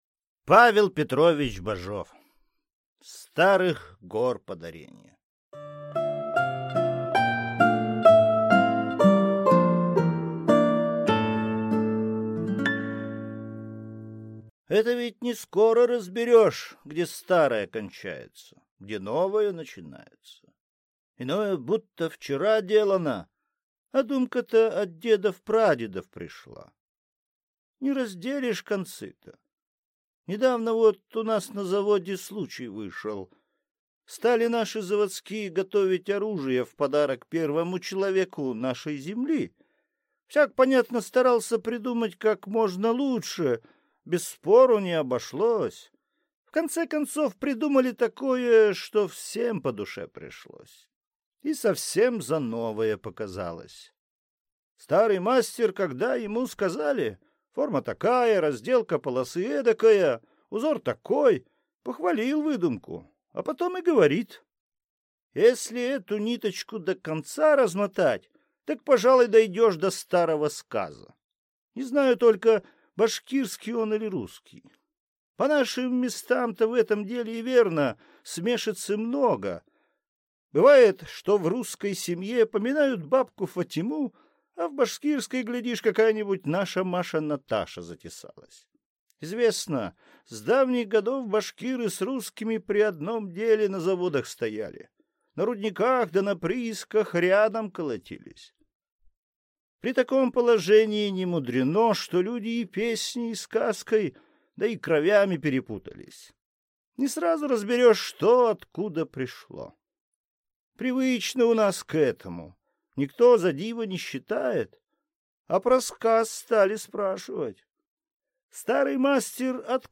Aудиокнига Старых гор подаренье